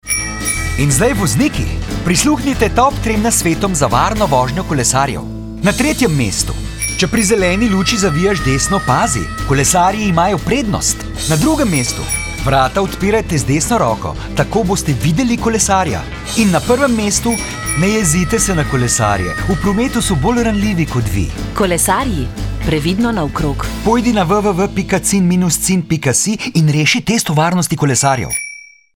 Radijski oglas s tremi nasveti za voznike motornih vozil  (mp3)